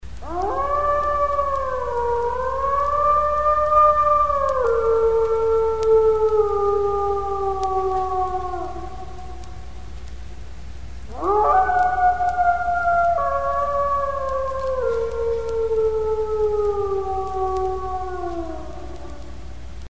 Звук волчьего воя на луну